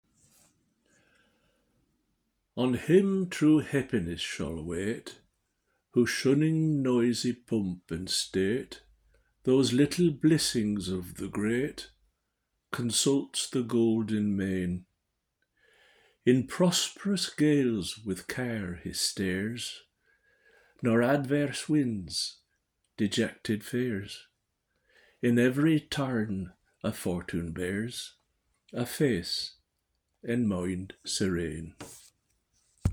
Readings from Franklin’s Poor Richard’s Almanack and his Reformed Mode of Spelling